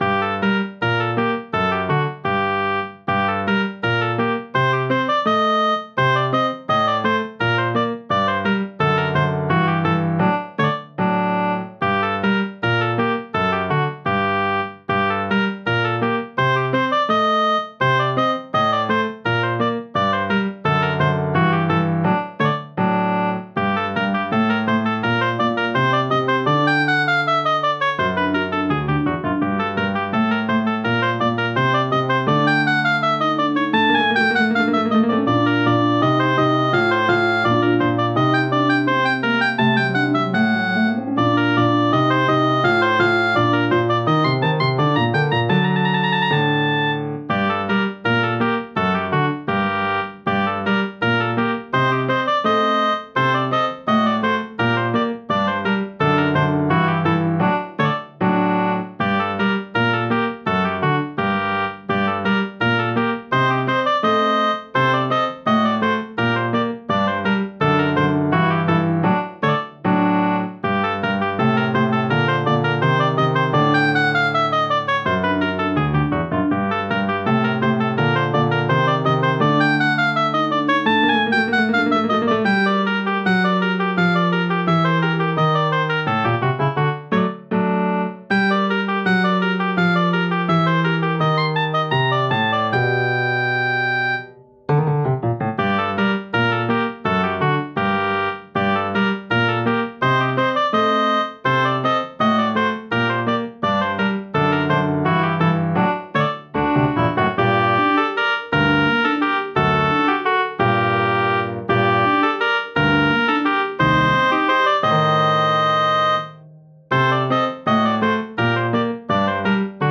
オーボエがぴょこぴょこはねる、ダークでクラシカルなBGMです。
速い、暗いコミカル